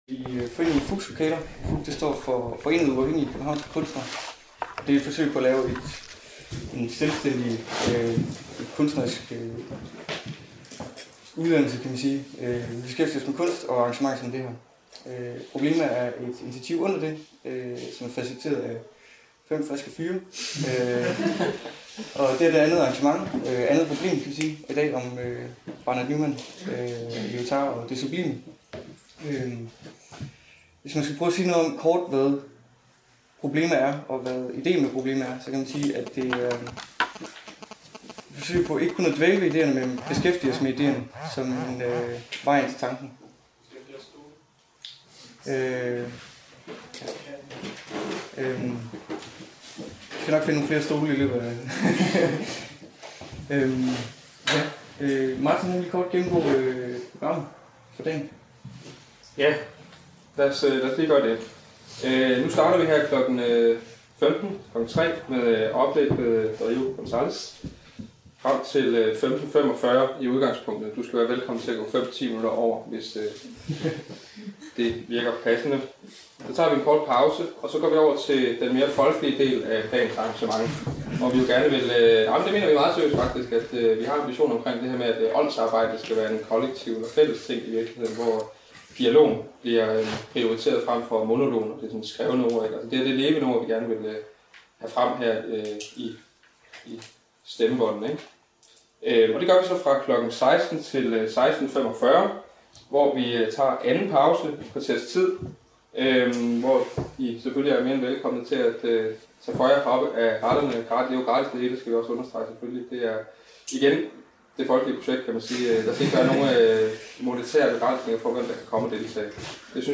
Værket som spørgsmål (Om Barnett Newman, Jean-Francois Lyotard og det sublime) Diskussion Kritik